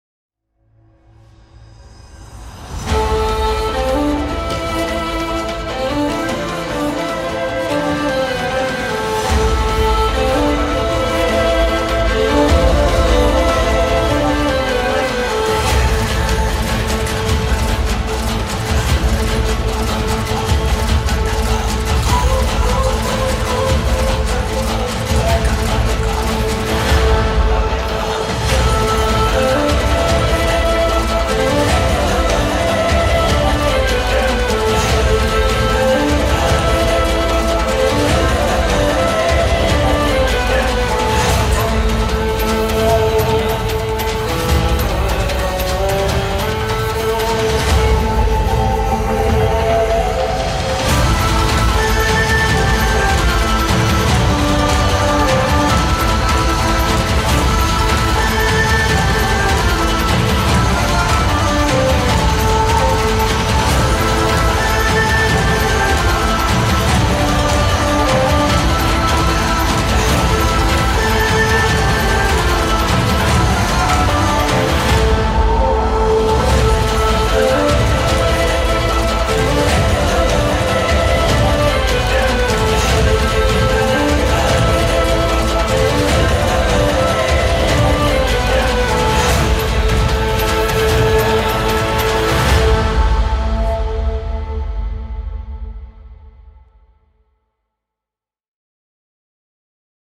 duygusal heyecan aksiyon fon müziği.